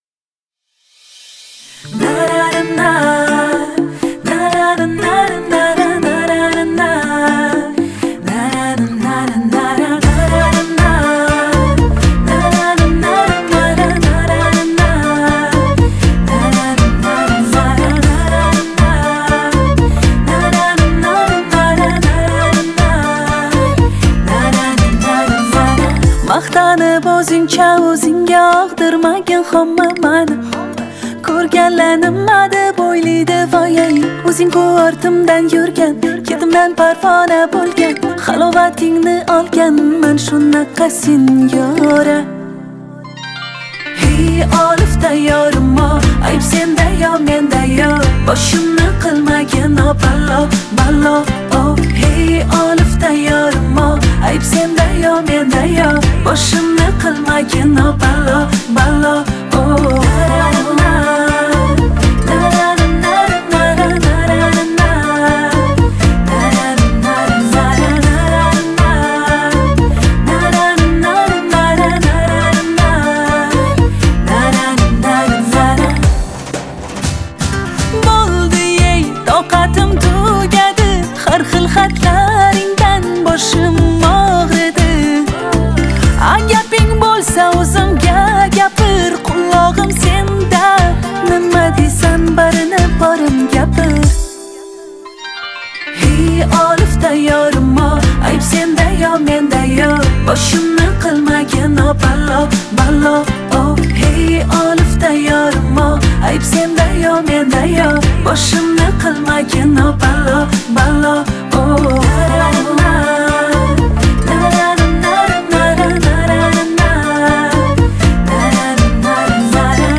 Трек размещён в разделе Узбекская музыка.